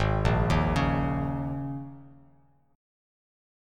G#sus2sus4 chord